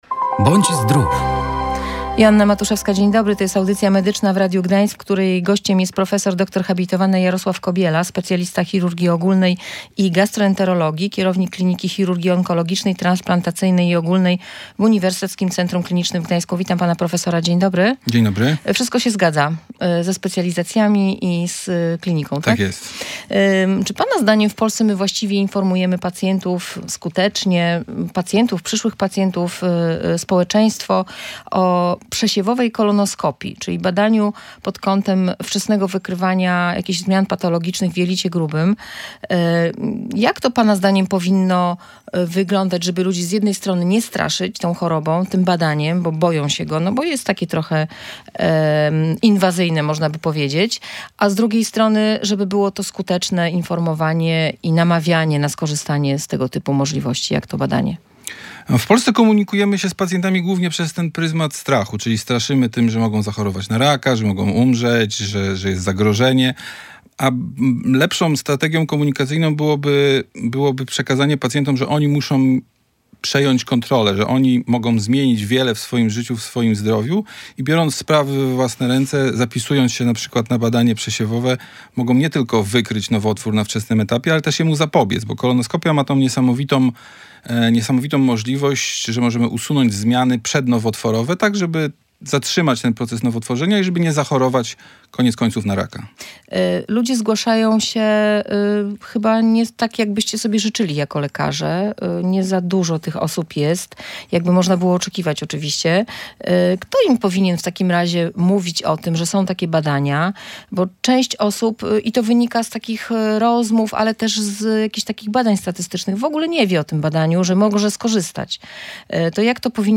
Styl życia i spożywanie wysokoprzetworzonej żywności już od najmłodszych lat zwiększa ryzyko zachorowania – mówił w audycji medycznej w Radiu Gdańsk prof. dr hab.